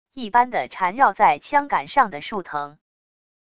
一般的缠绕在枪杆上的树藤wav音频